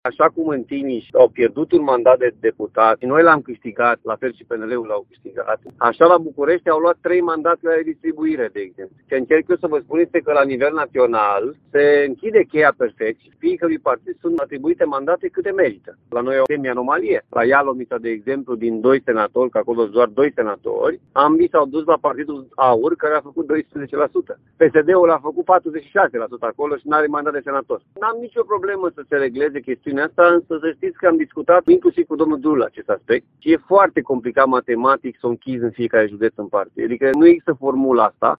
Președintele PSD Timiș, Alfred Simonis, reales deputat, admite că sunt unele anomalii, dar nu vede soluția pentru un sistem care să elimine, în totalitate, astfel de situații.